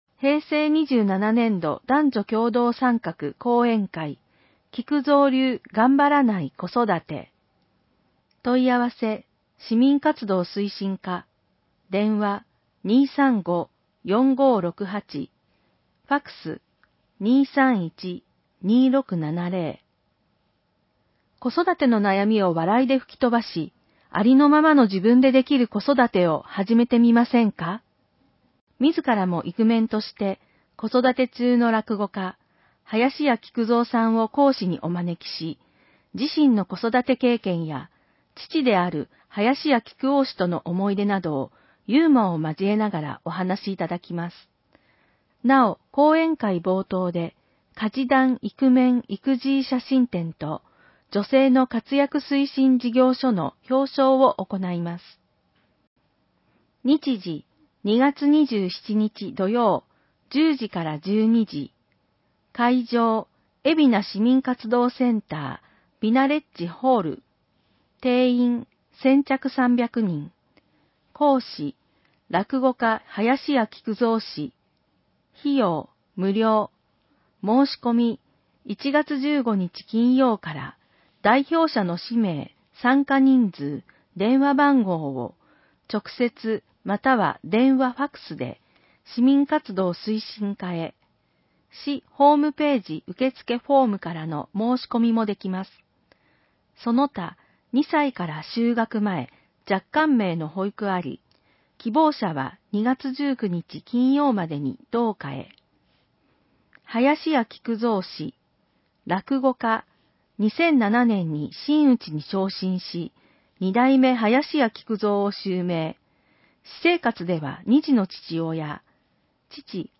広報えびな 平成28年1月15日号（電子ブック） （外部リンク） PDF・音声版 ※音声版は、音声訳ボランティア「矢ぐるまの会」の協力により、同会が視覚障がい者の方のために作成したものを登載しています。